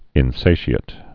(ĭn-sāshē-ĭt)